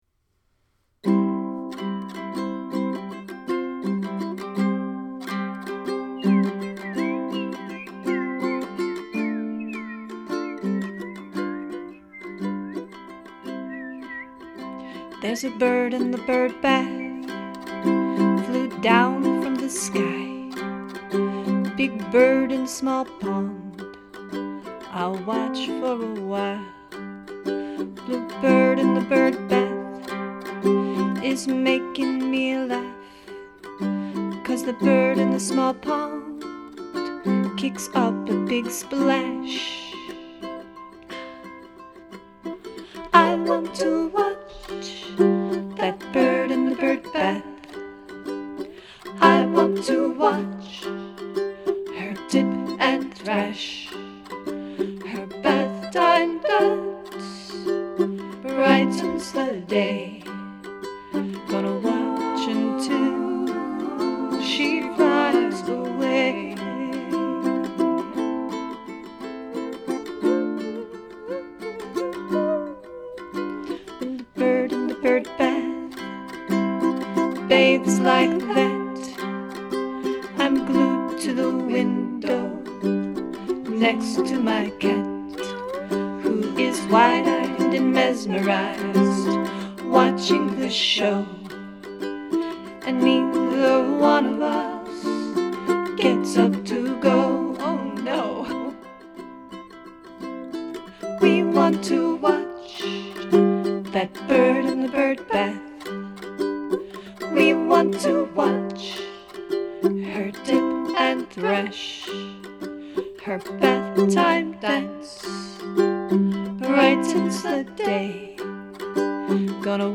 Vocals
Ukulele